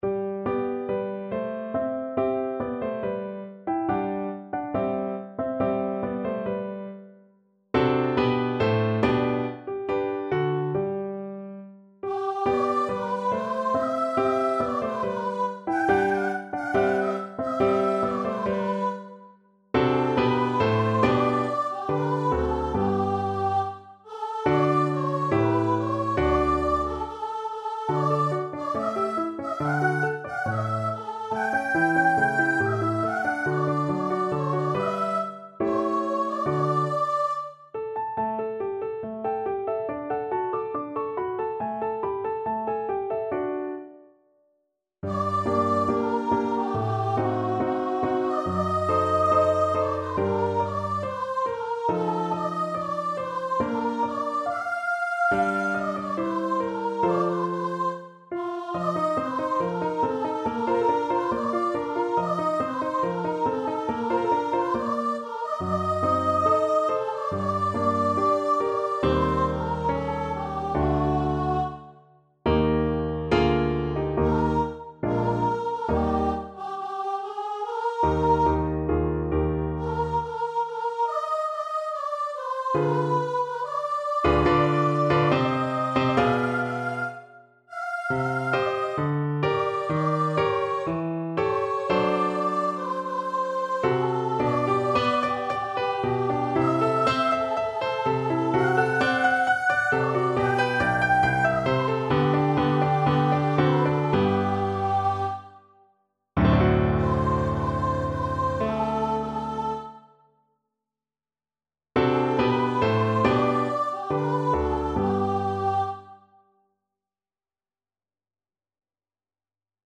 Free Sheet music for Soprano Voice
Voice  (View more Intermediate Voice Music)
Classical (View more Classical Voice Music)